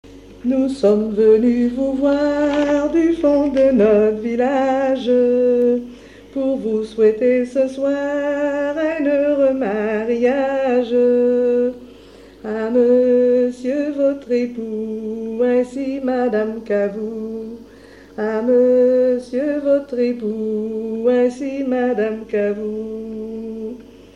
chantée durant le repas de noce
Genre strophique
Pièce musicale inédite